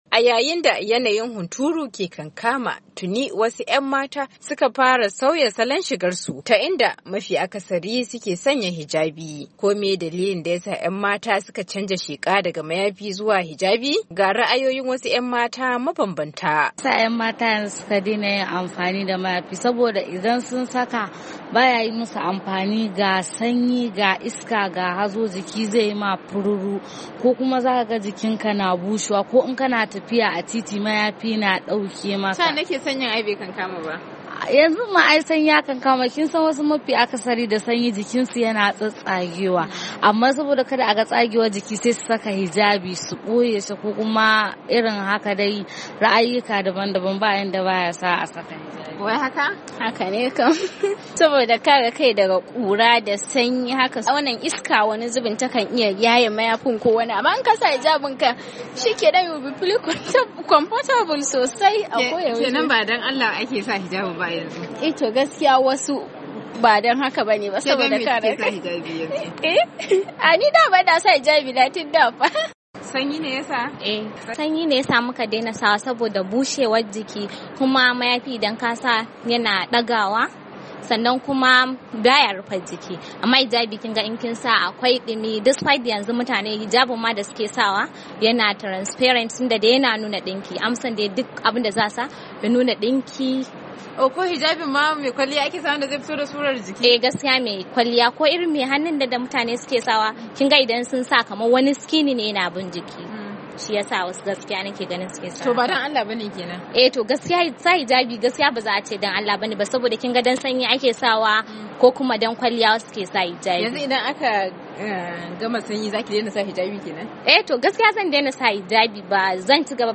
Akan haka ne muka sami zantawa da wasu mata matasa dangane da sauyin tufafi a wannan lokaci.